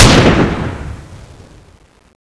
fire.wav